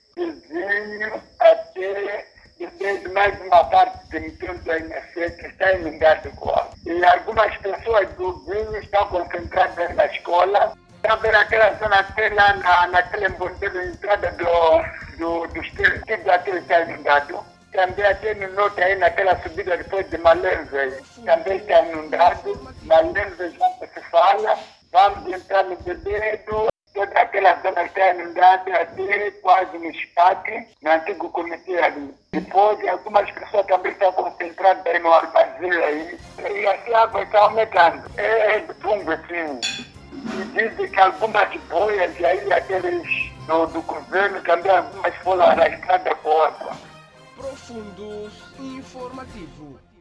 Escute o áudio em entrevista